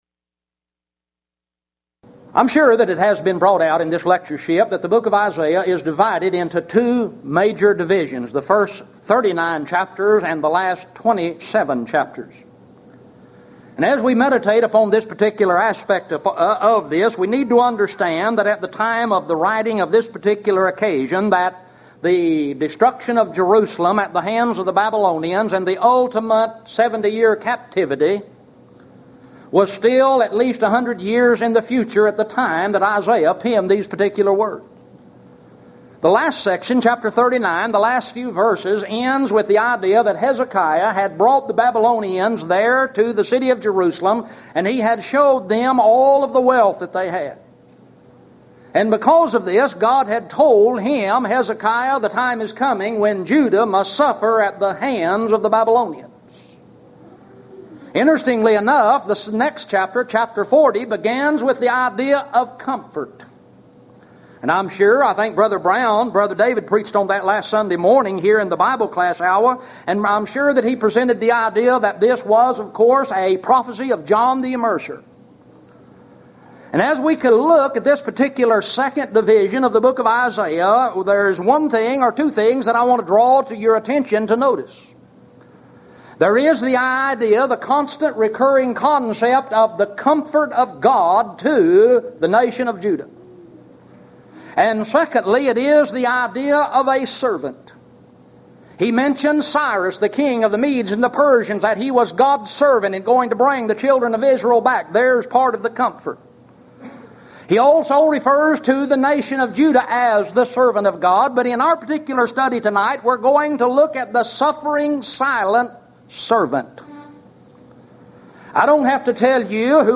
Series: Houston College of the Bible Lectures Event: 1996 HCB Lectures